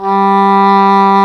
Index of /90_sSampleCDs/Roland L-CDX-03 Disk 1/WND_English Horn/WND_Eng Horn 2